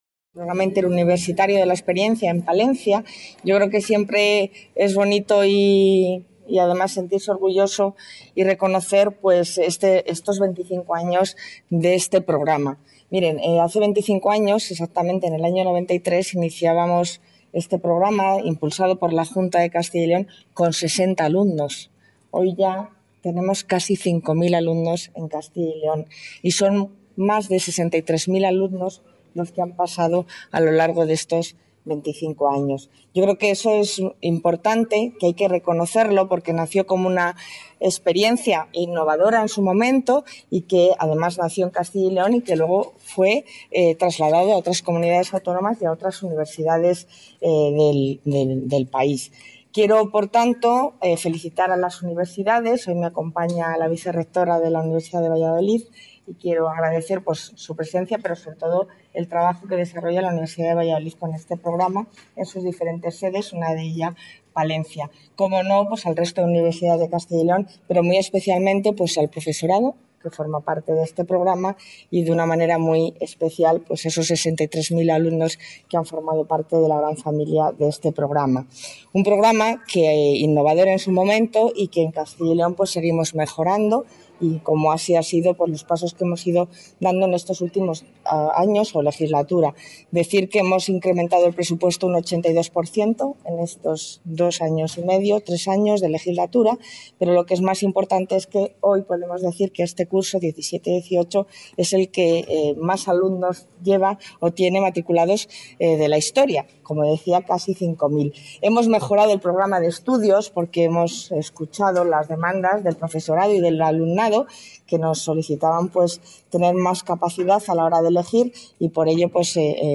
Declaraciones de la consejera de Familia e Igualdad de Oportunidades.